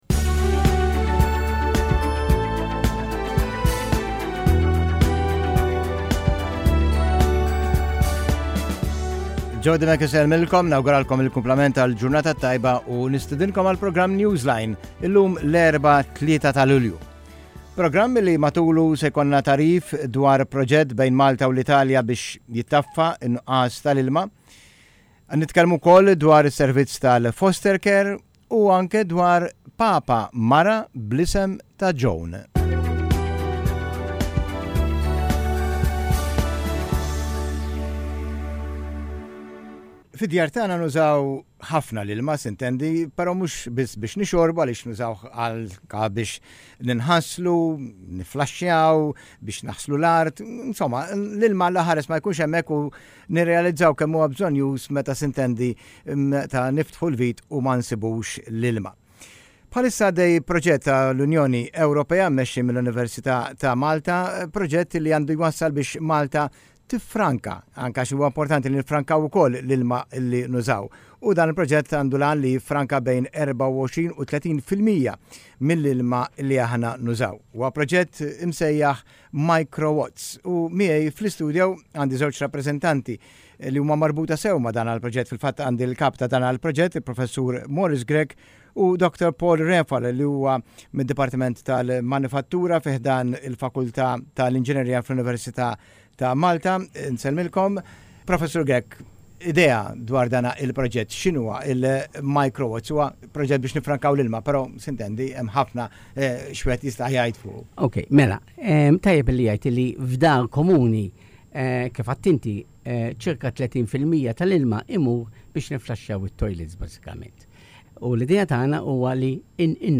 Micro WatTS Radio Interview - Micro WatTS